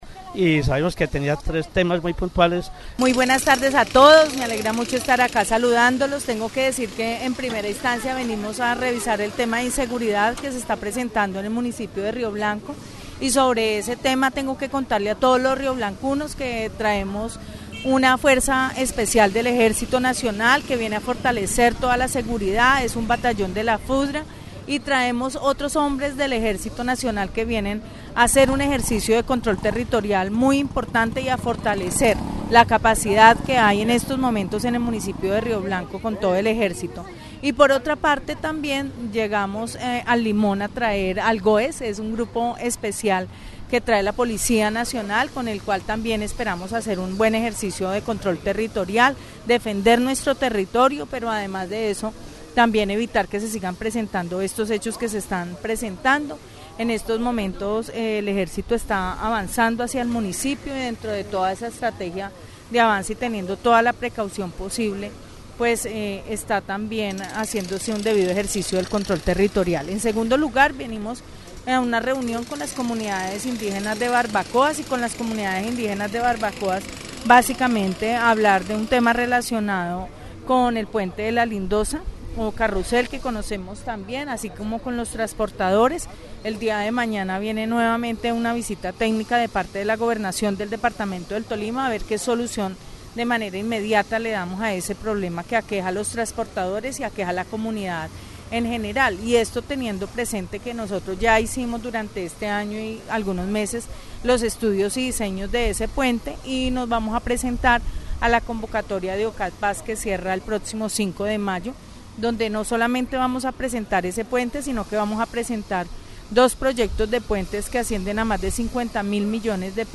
En las mismas declaraciones que le fueron concedidas a la emisora local Rioblanco Estéreo, la gobernadora se refirió, también, a la fiebre amarilla.
Escuche las declaraciones de la gobernadora del Tolima: